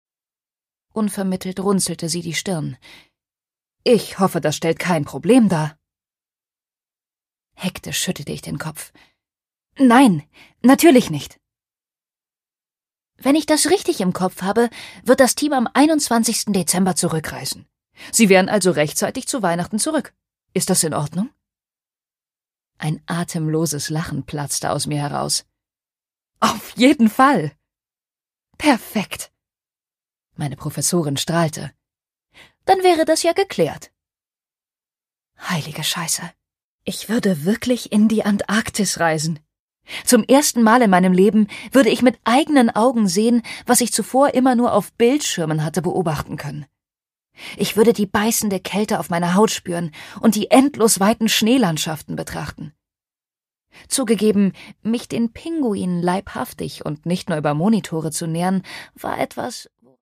Greta Milán: The Penguin Paradox (Ungekürzte Lesung)
Produkttyp: Hörbuch-Download